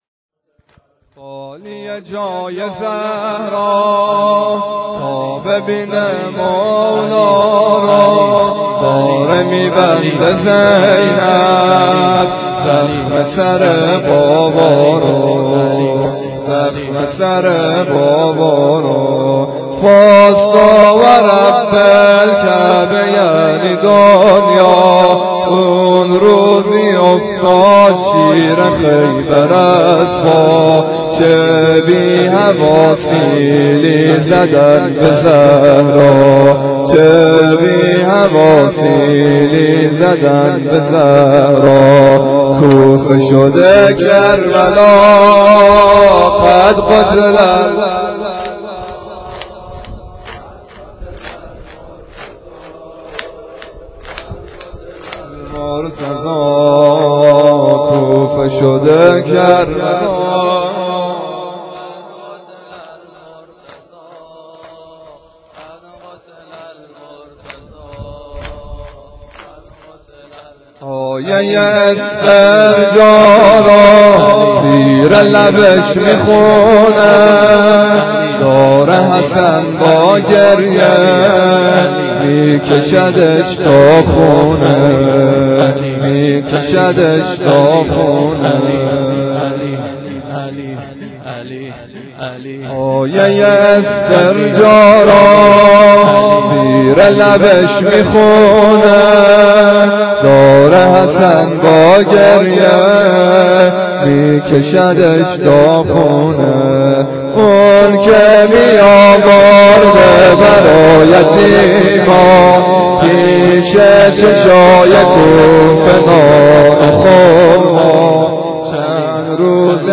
کوفه شده کربلا _ زمینه